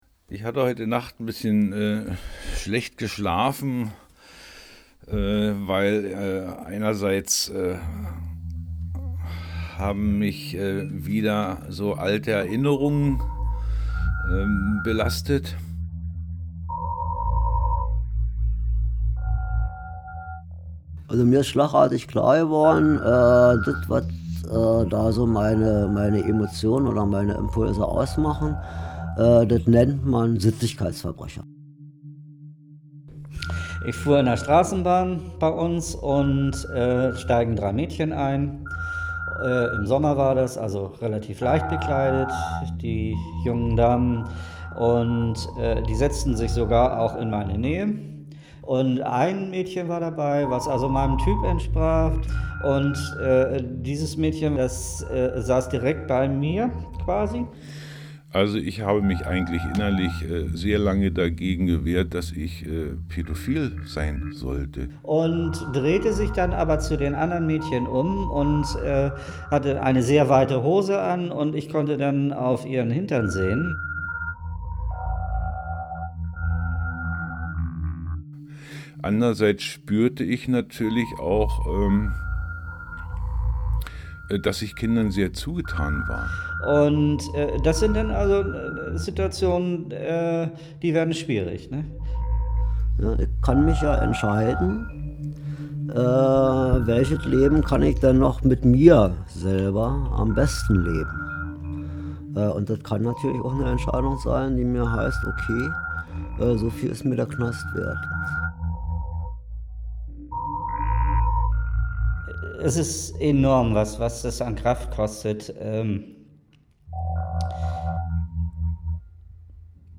radio-feature